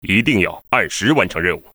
文件 文件历史 文件用途 全域文件用途 Bk_fw_03.ogg （Ogg Vorbis声音文件，长度1.7秒，114 kbps，文件大小：24 KB） 源地址:游戏语音 文件历史 点击某个日期/时间查看对应时刻的文件。